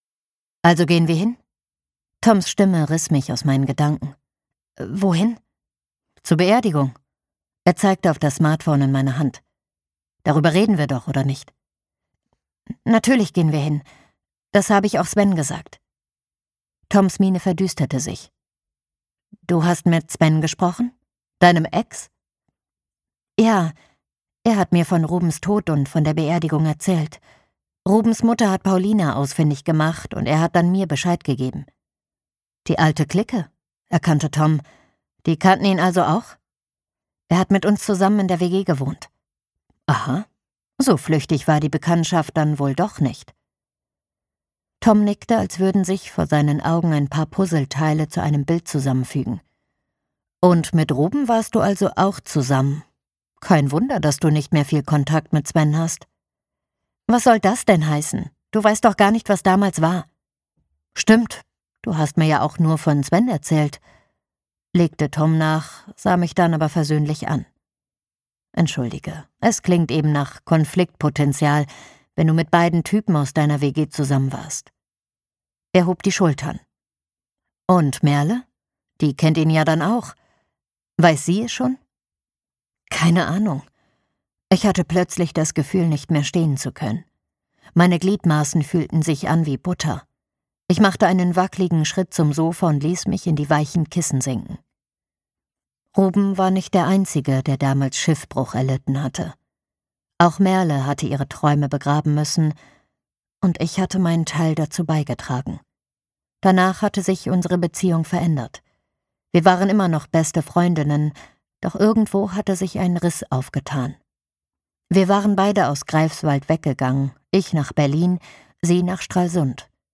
Wer am Ende übrig bleibt - Mark Flemming | argon hörbuch
Gekürzt Autorisierte, d.h. von Autor:innen und / oder Verlagen freigegebene, bearbeitete Fassung.